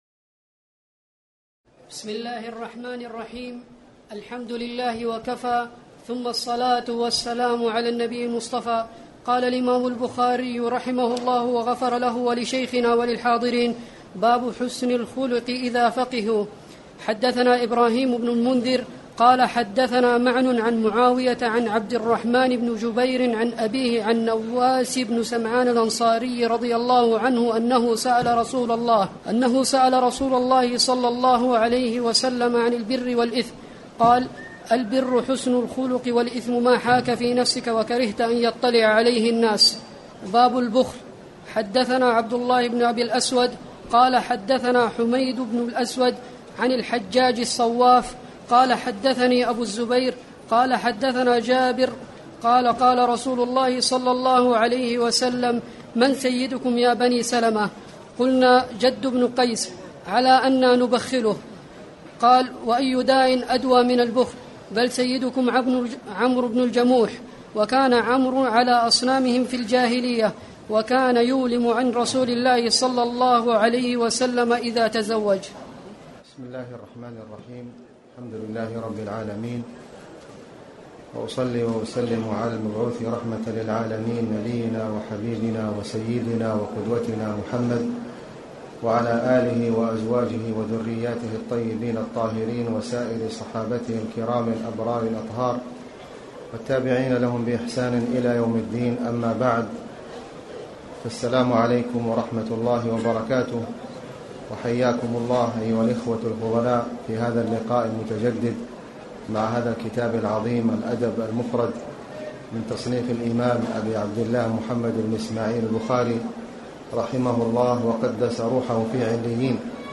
تاريخ النشر ٨ ذو القعدة ١٤٣٨ هـ المكان: المسجد الحرام الشيخ: فضيلة الشيخ د. خالد بن علي الغامدي فضيلة الشيخ د. خالد بن علي الغامدي باب حسن الخلق إذافقهوا